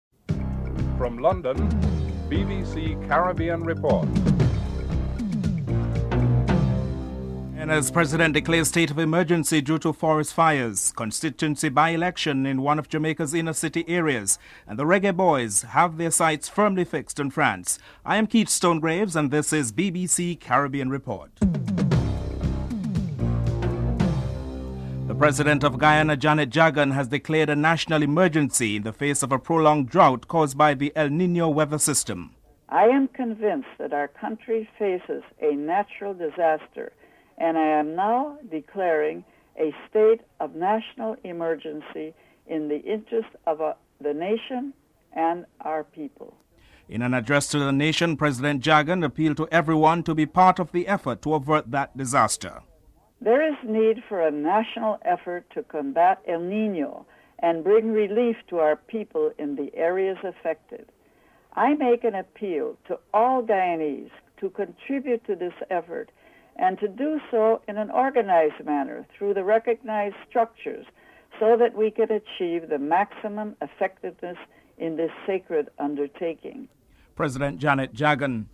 Coach Rene Simoes comments on the team performance (09:54-12:59)
9. Recap of top stories (14:51-15:10)